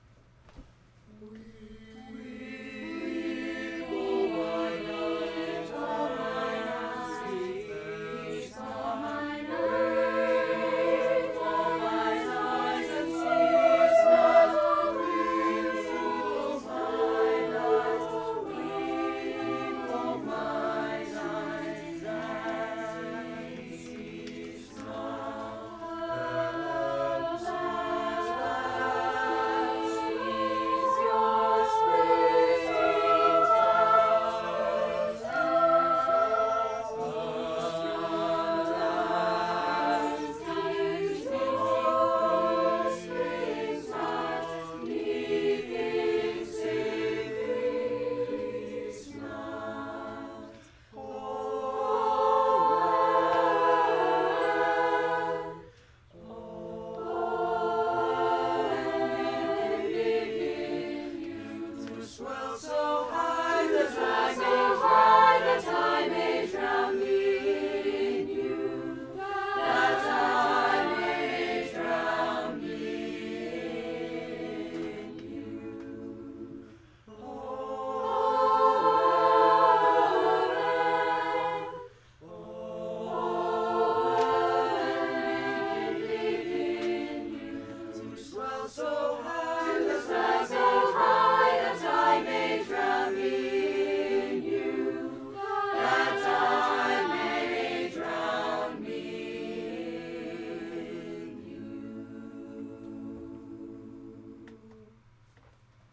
2:00 PM on July 20, 2014, "Music with a View"
Madrigals